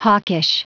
Prononciation du mot hawkish en anglais (fichier audio)
Prononciation du mot : hawkish